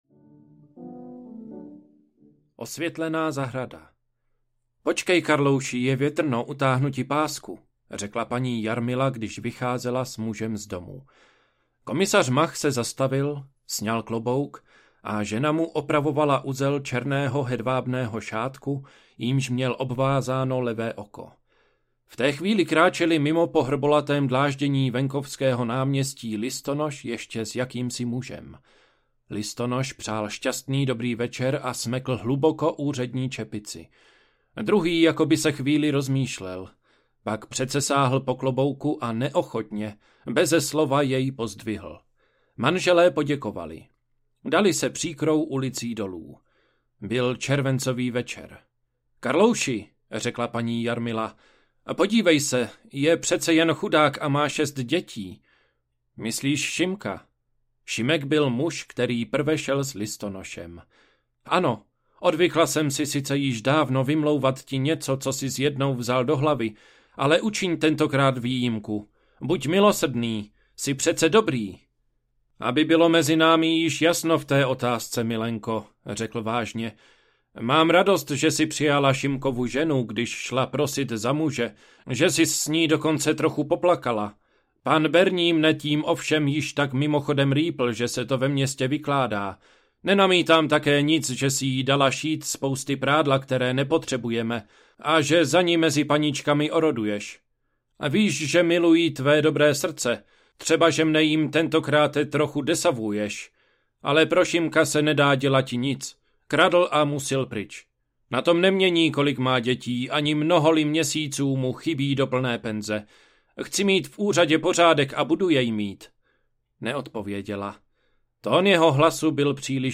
Žalář nejtemnější audiokniha
Ukázka z knihy